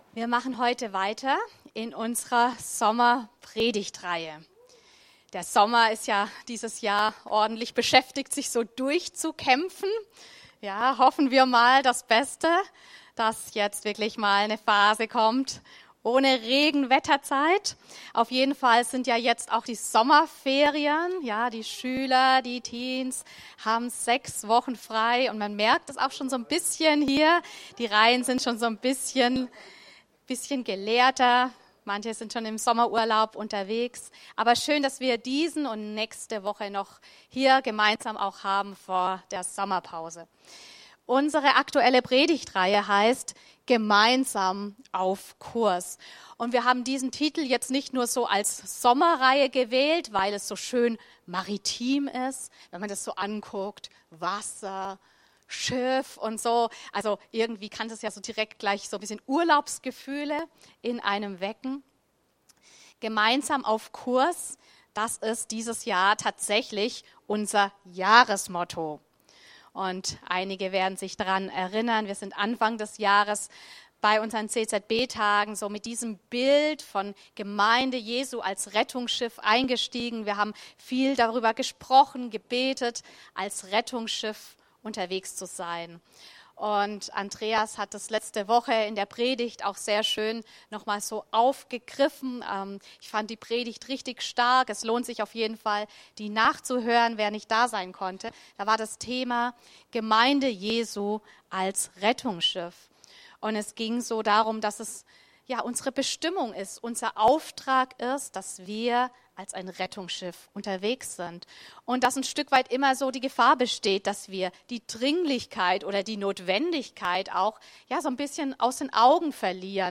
Predigten
Aktuelle Predigten aus unseren Gottesdiensten und Veranstaltungen